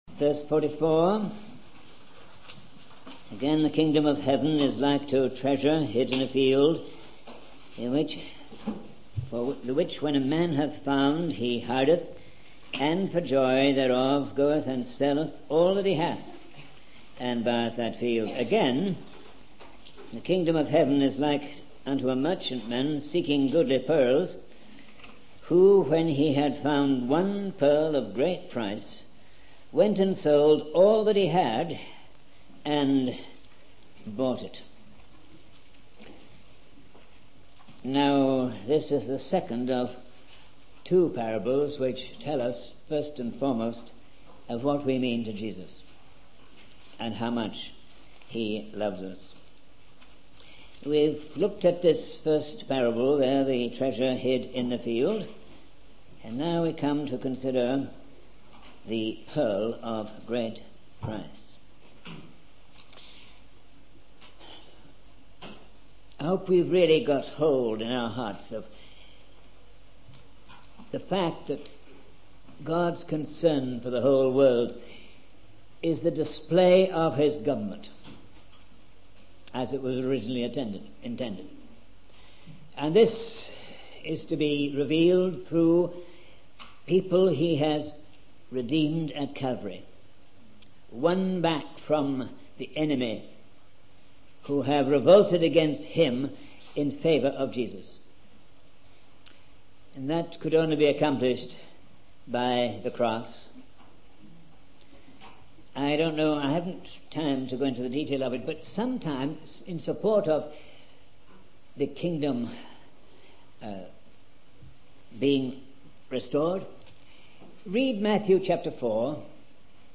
In this sermon, the speaker shares a personal experience of being hurt by someone's negative comment about the church attendance.